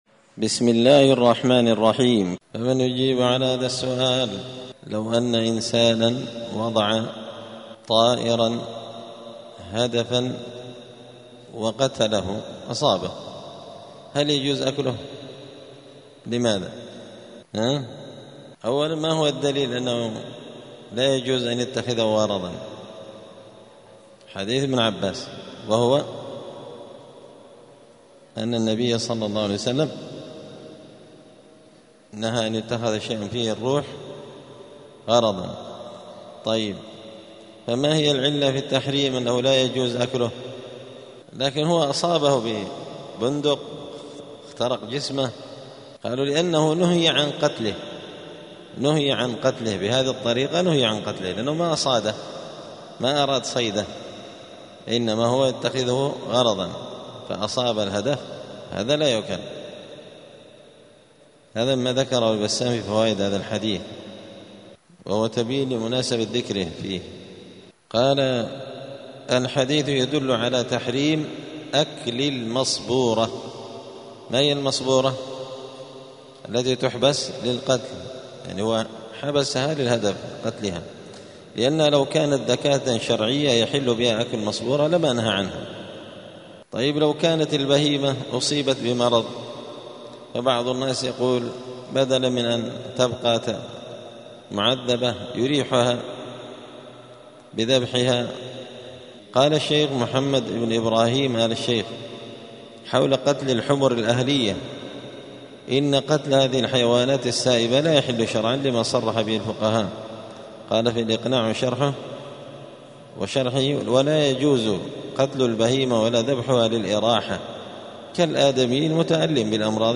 دار الحديث السلفية بمسجد الفرقان قشن المهرة اليمن 📌الدروس اليومية